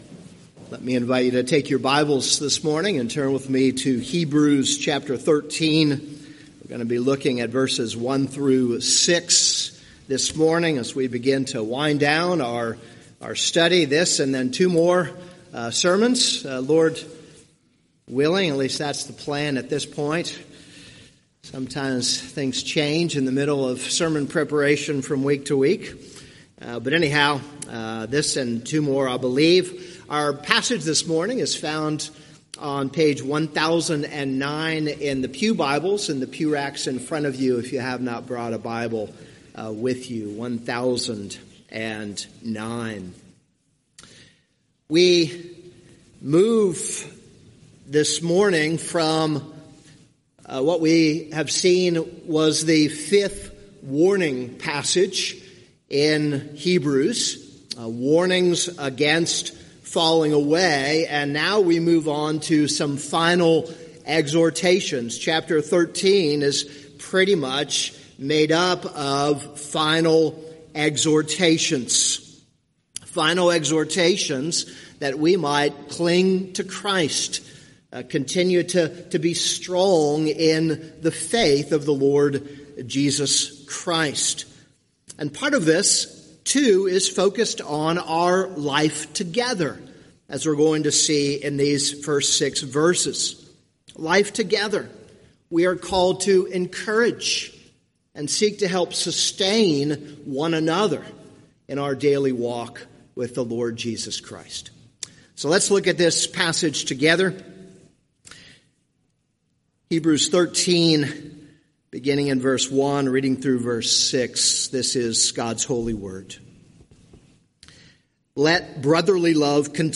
This is a sermon on Hebrews 13:1-6.